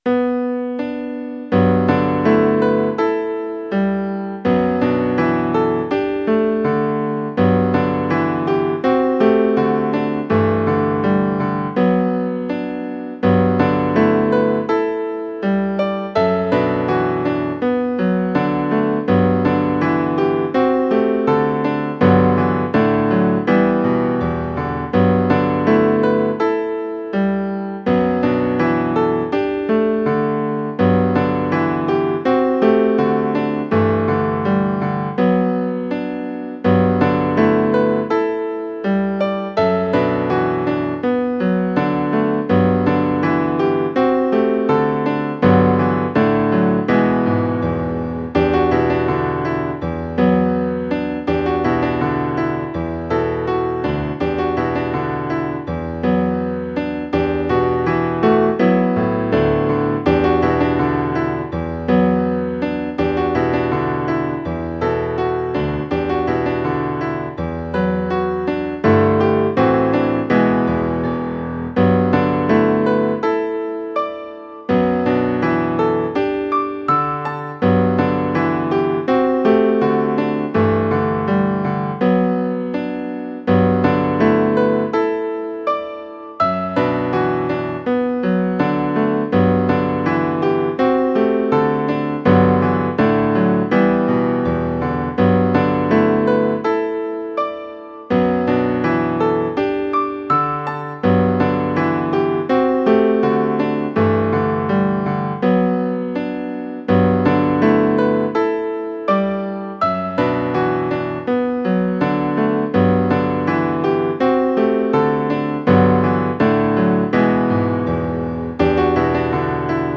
ゆったりとしたテンポのジブリ風ピアノソロを無料音楽素材として公開しています。
イメージ：再会　ジャンル：ジブリっぽいピアノソロ
コメント：曲の感じが、哀愁と少しの希望でしたので、「また会いましょう。」という題名にしました。